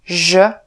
pleasure, treasure
Slovak voice announciation